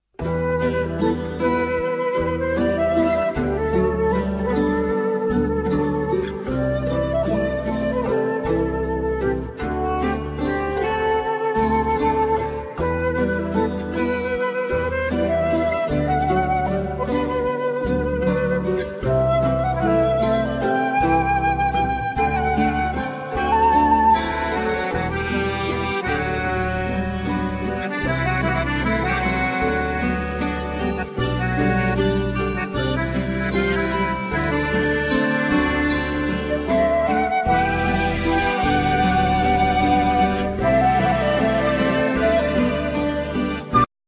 Accordion
Flute
Mandolin
Cello